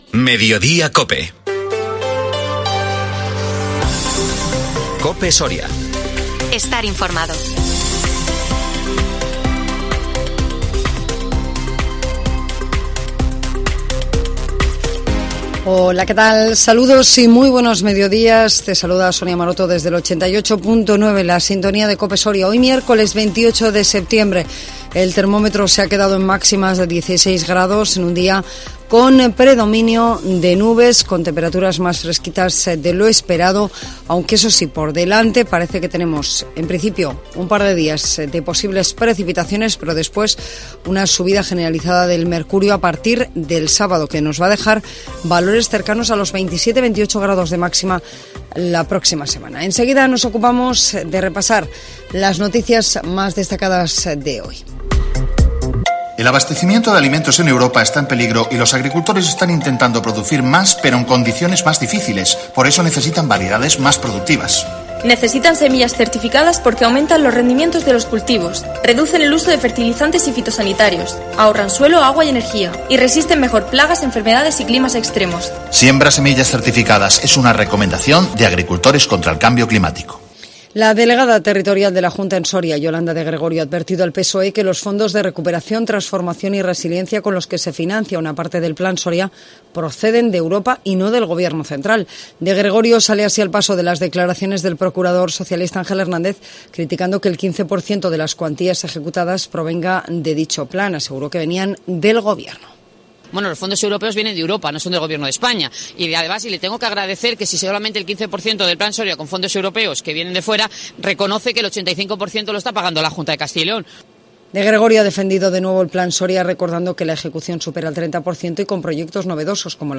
INFORMATIVO MEDIODÍA COPE SORIA 28 SEPTIEMBRE 2022